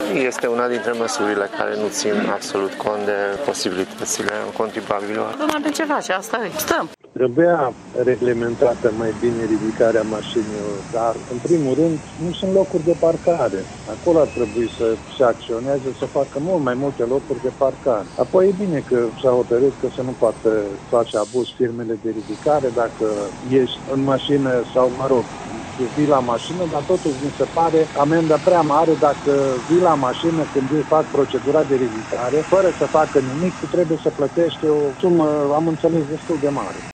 Tîrgumureşenii nu sunt foarte încântaţi de noul sistem, mai ales că nu sunt suficiente locuri de parcare, iar taxele pentru recuperarea autoturismului sunt mari: